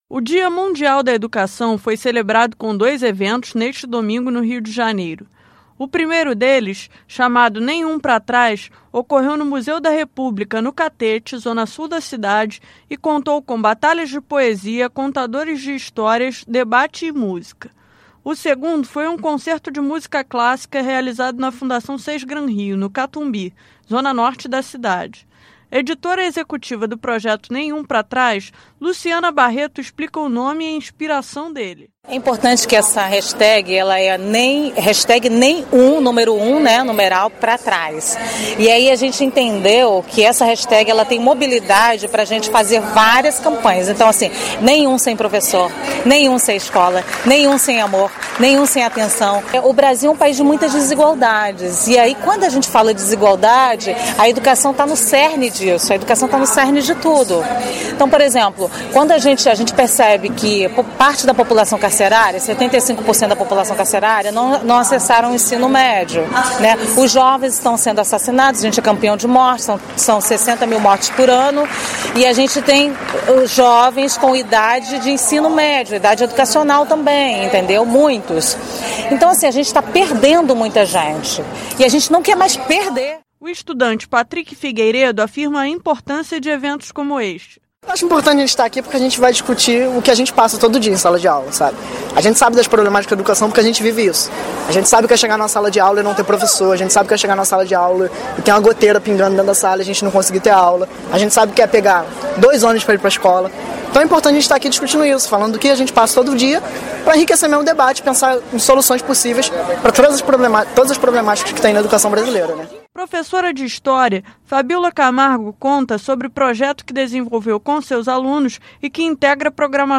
Confira detalhes na reportagem.